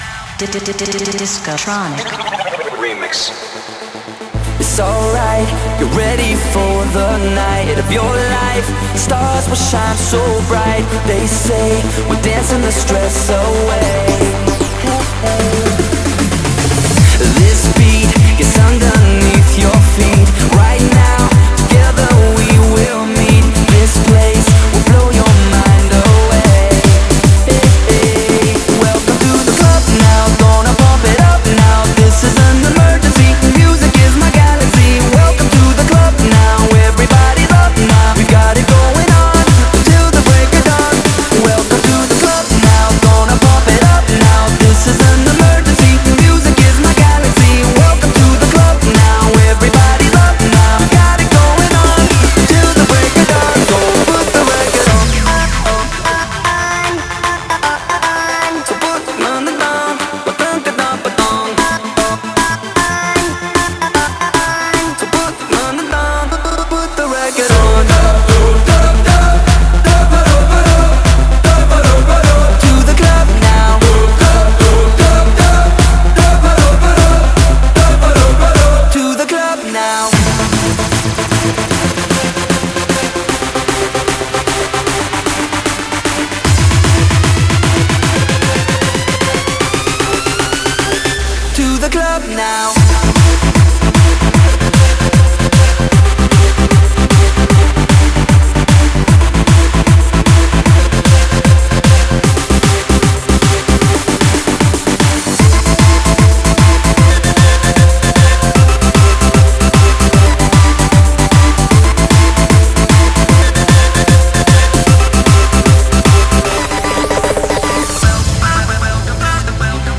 BPM152